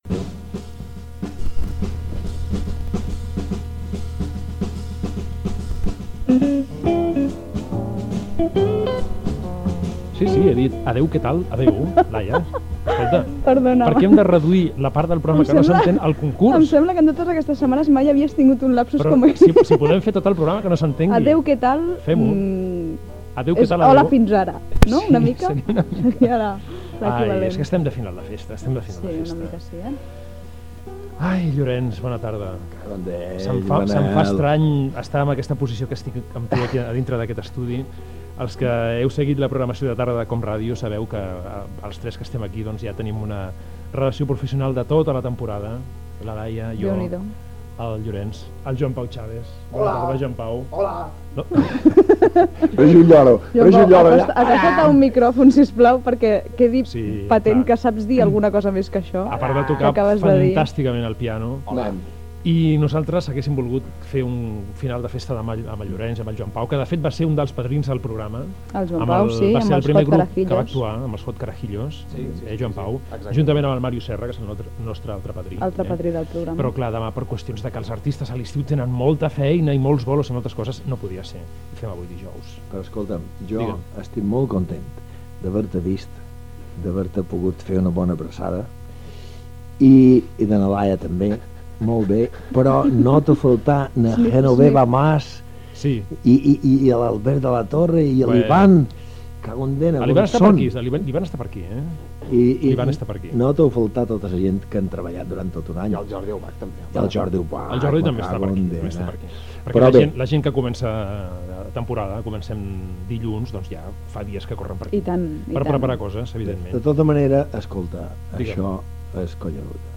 Diàleg inicial
Gènere radiofònic Entreteniment